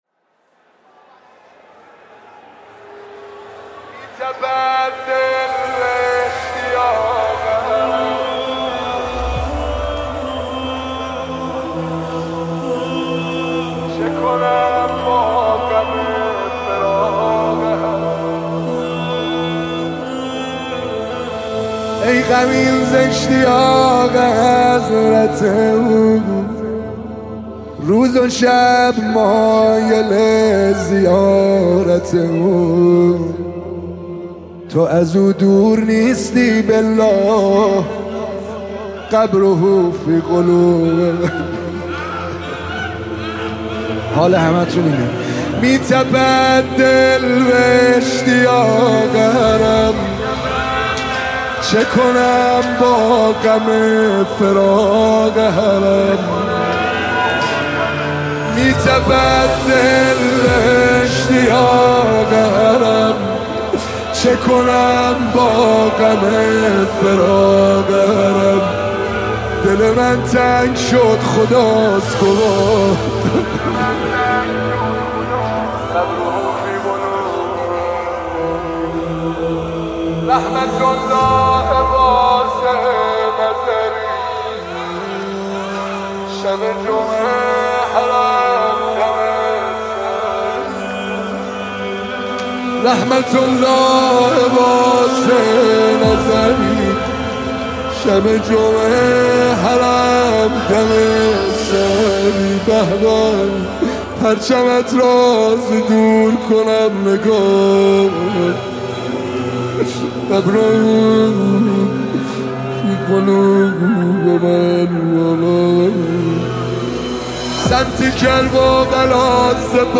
مداحی امام حسین